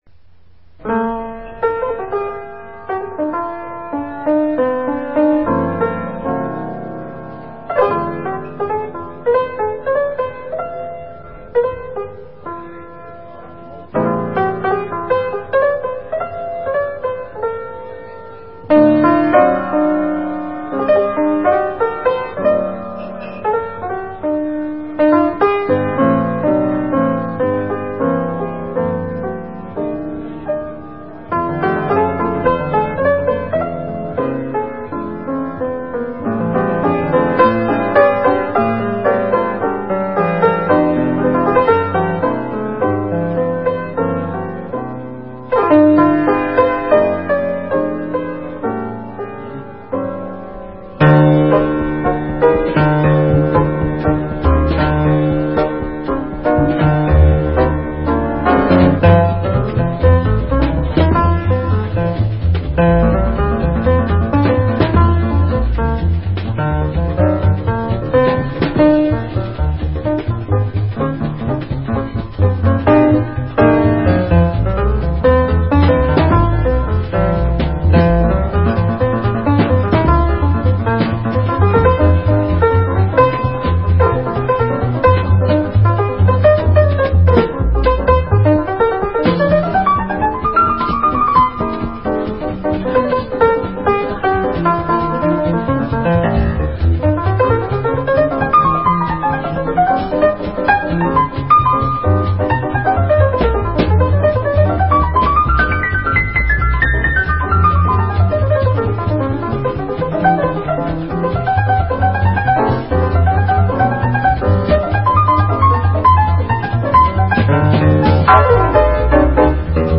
piano
bass
drums
These selections are taken from a pre-production tape.